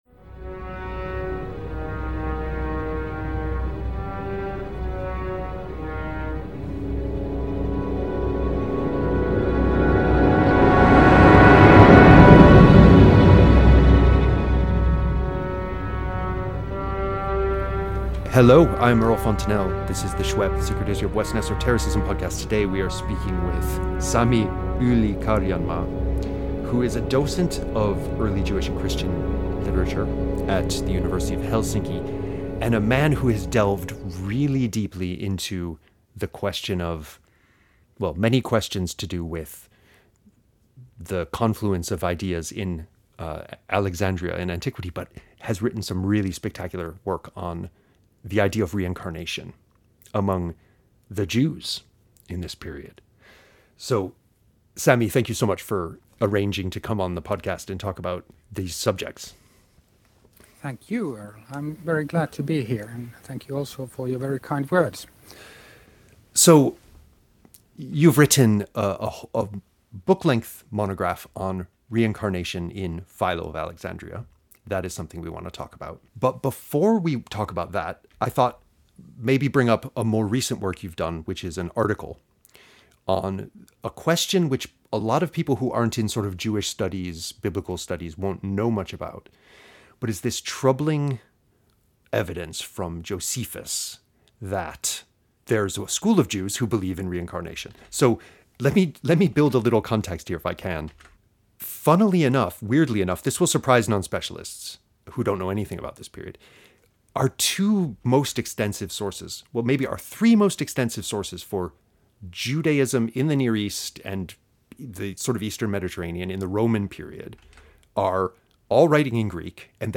[Special thanks to the weird motor which periodically starts up in the background of this recording] Philo of Alexandria and Josephus are our two best sources for Judaism in the crucial period before and during the great Jewish war, when the Temple is destroyed. How strange, then, that both authors indicate belief in reincarnation, which, it is often averred, does not become a Jewish belief until the rise, much later, of Kabbalistic notions in the later medieval period.